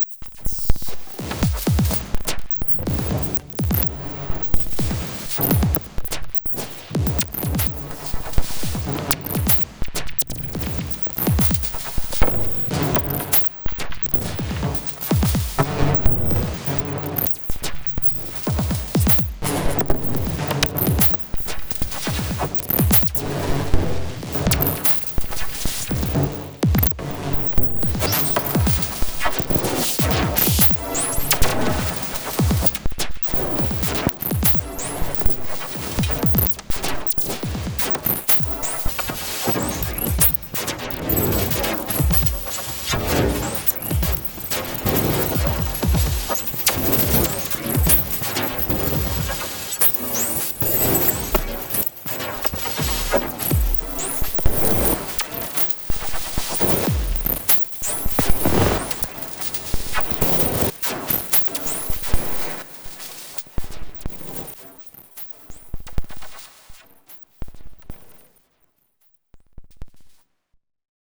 Currently preparing for a small live set with the a4, here’s an excerpt of 3 tracks through overdubbing torso s-4 with effects to enhance the fuzziness
generally I have a soft spot for the s-4 so def not a neutral answer here. as for pairing with the a4, can’t say i’ve established a solid workflow for the two, but soundwise i reckon the s-4 brightens the otherwise dark sound of the a4 in a nice way.